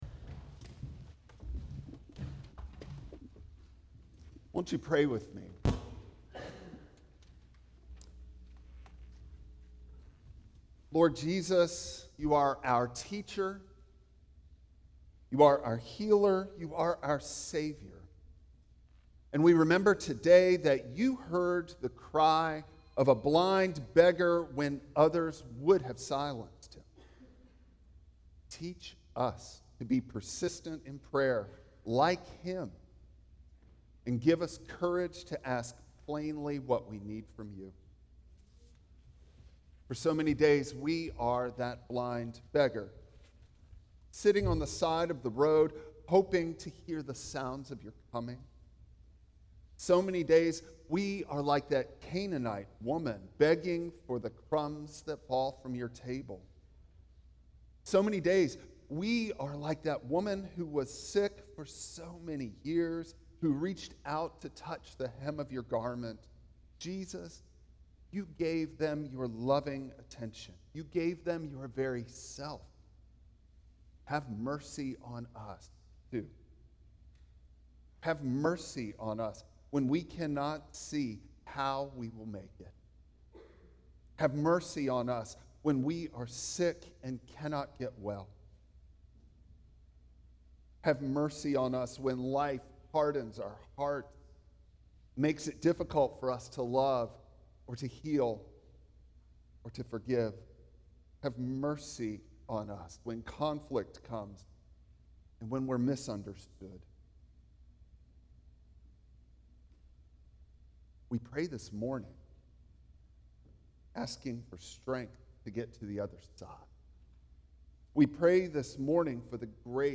Bible Text: Mark 10:46-52 | Preacher